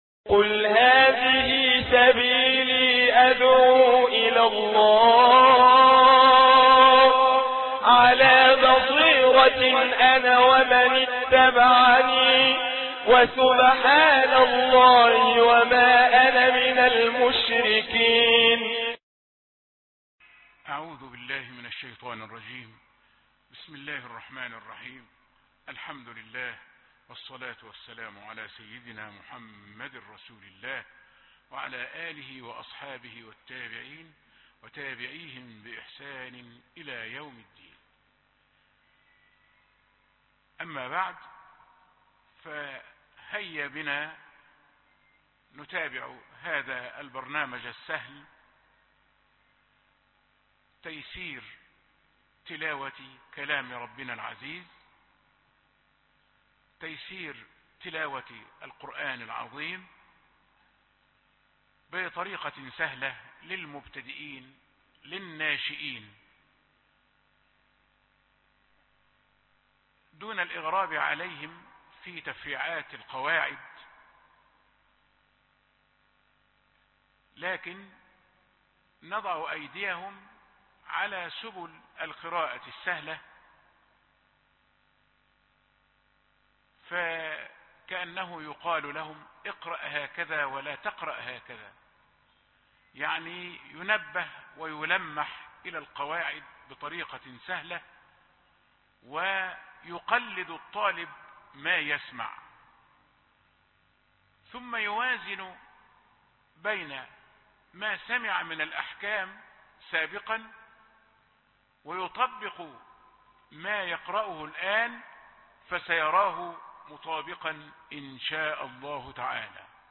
سورة المدثر2 - تيسير تلاوة القران برواية حفص